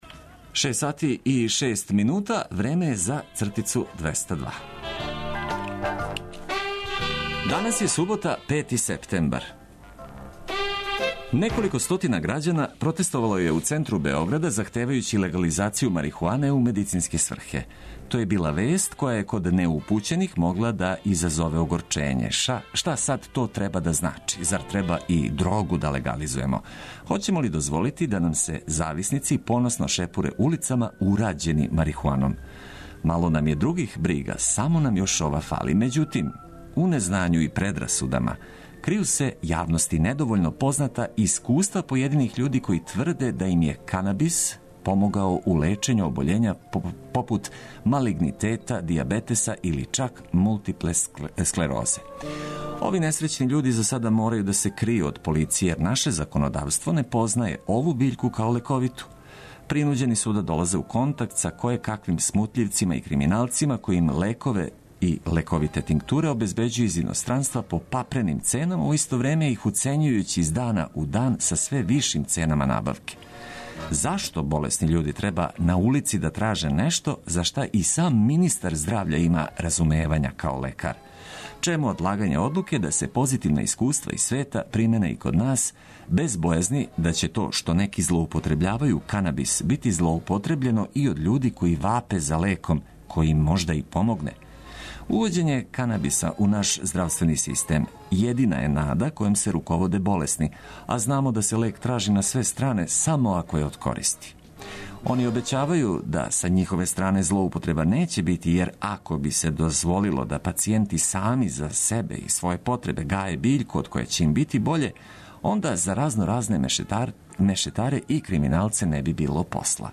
Почетак викенда обележићемо пријатним буђењем уз много корисних информација и музике која убрзава растанак са постељом.